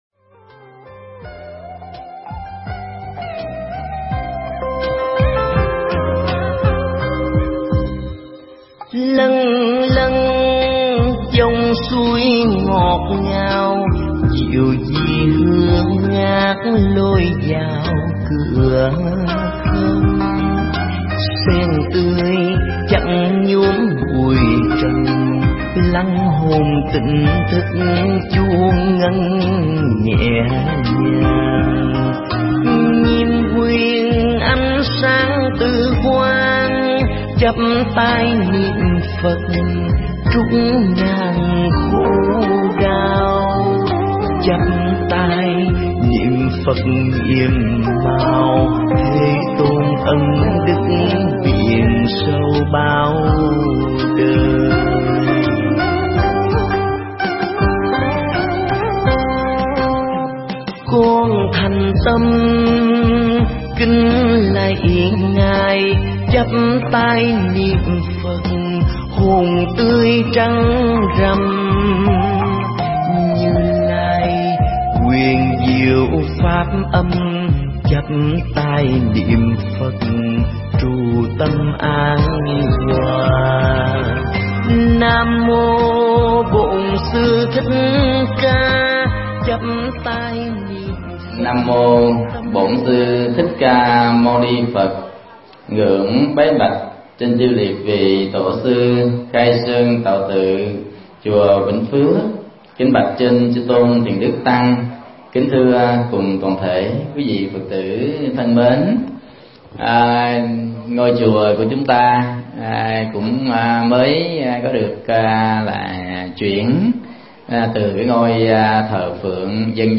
Mp3 Thuyết Pháp Tiến Trình Thanh Lọc Tâm (Ý nghĩa)
thuyết giảng ở Chùa Vĩnh Phước – đường Trần Quang Diệu phường 14, Quận 3, TP.HCM